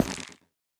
Minecraft Version Minecraft Version latest Latest Release | Latest Snapshot latest / assets / minecraft / sounds / block / nether_wood_hanging_sign / step1.ogg Compare With Compare With Latest Release | Latest Snapshot
step1.ogg